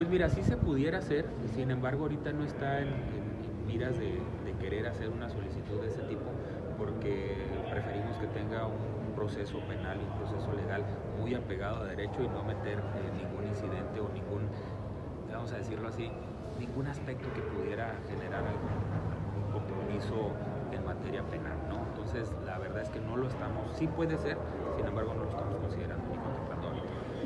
Interrogado sobre si solicitaría que el armamento pase a control de los agentes policiales, el titular de la Secretaría de Seguridad Pública del Estado (SSPE), Gilberto Loya Chávez, mencionó que, si bien resulta posible, no lo ha contemplado de momento.